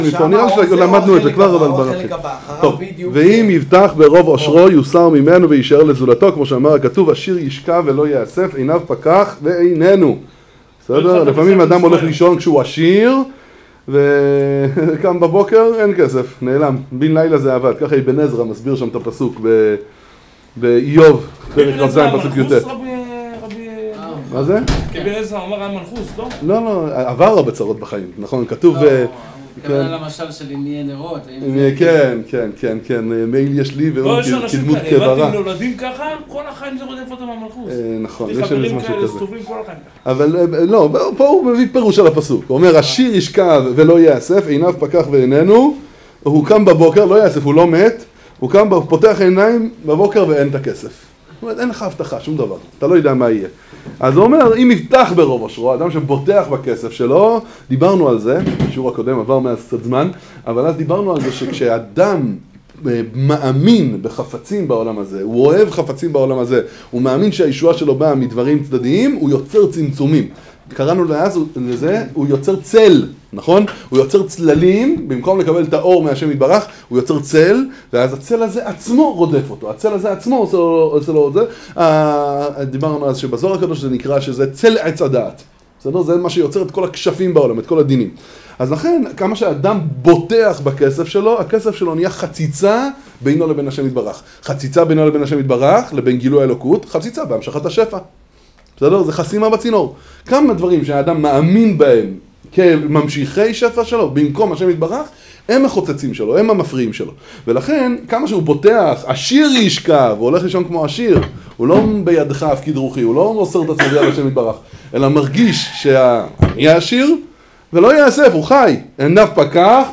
שיעור 3